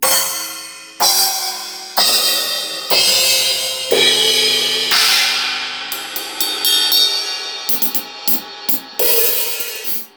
Ich hab mal ein Soundsample meiner aktuellen Becken angehangen.